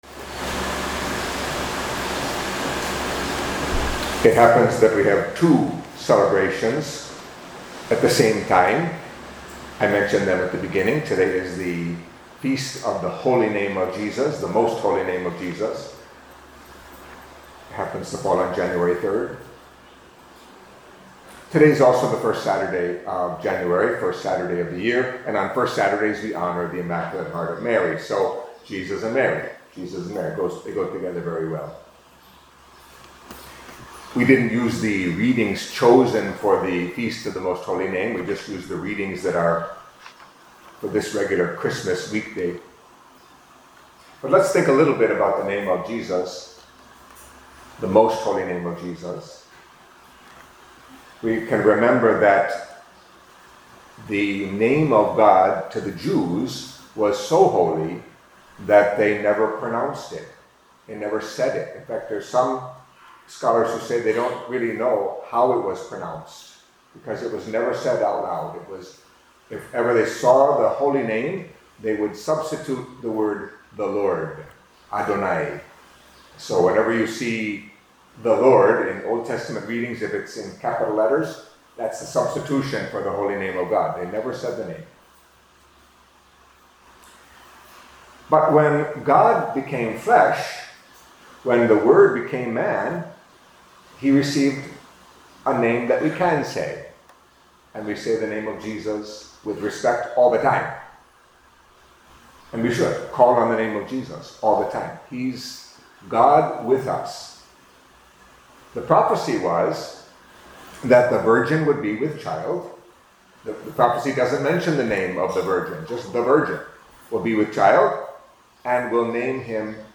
Catholic Mass homily for Saturday